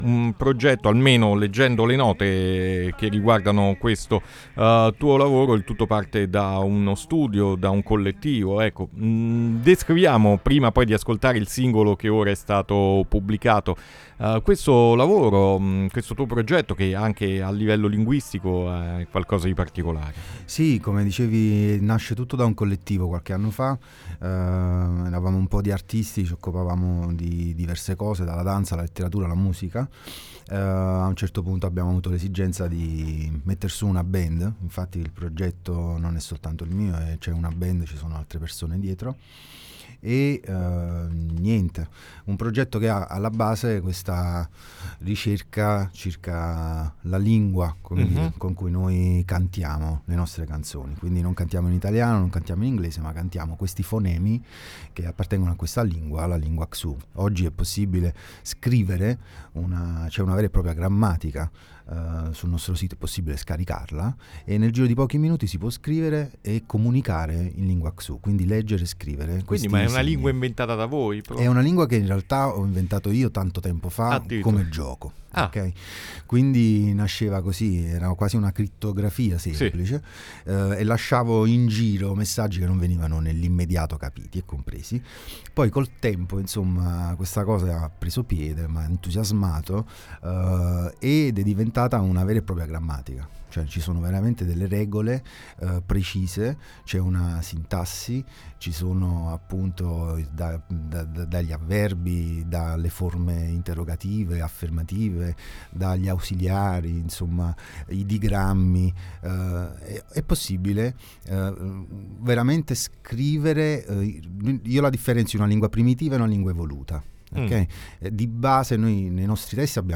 Interviste Mercoledì Morning